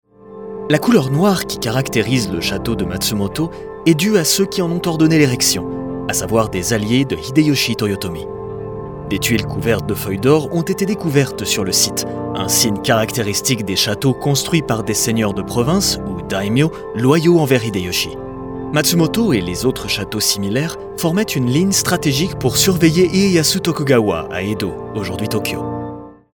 Audio Guides
My natural voice lies in the middle-low range and is perfect to inspire and create trust with a touch of warmth and accessibility – ideal for corporate jobs, e-learnings and other “serious” projects that demand a reliable & inspiring yet warm and friendly delivery.